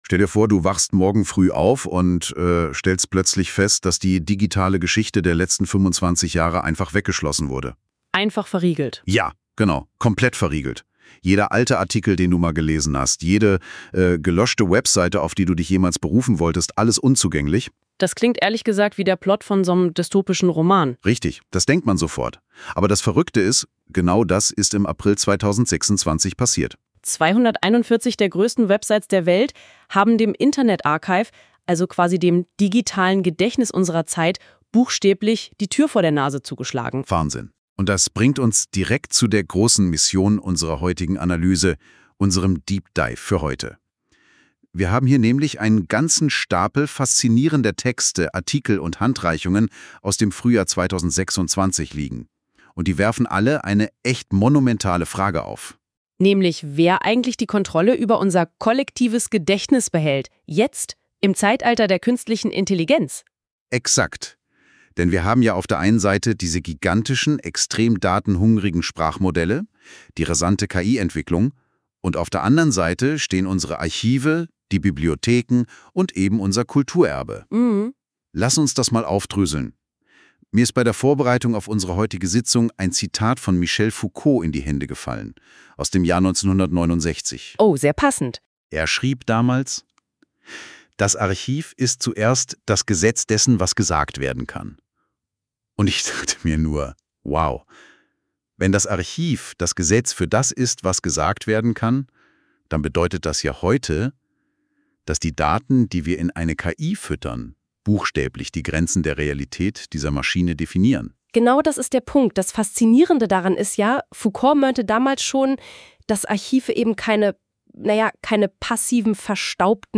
Dann hör Dir die KI-generierte Podcast-Version dieser Ausgabe an – einfach auf Play drücken.